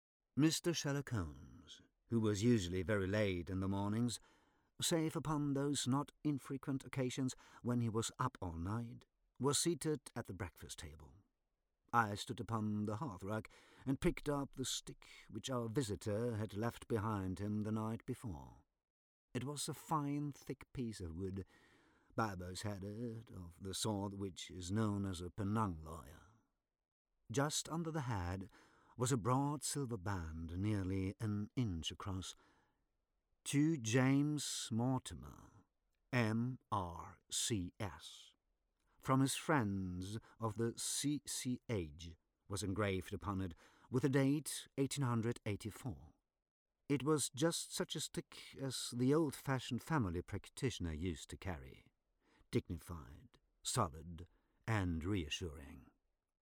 Sprecherdemos
Erzählung: Sherlock Holmes